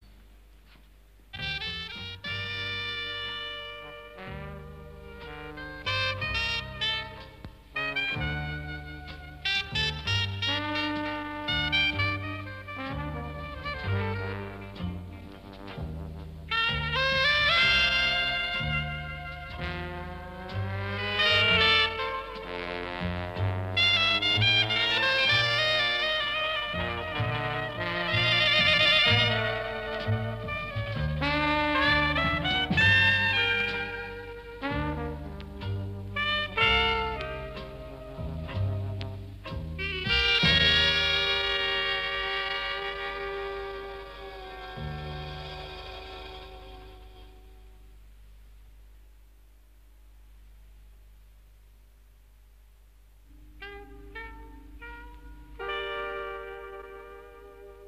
Прозвучал он треком в известном теле-спектакле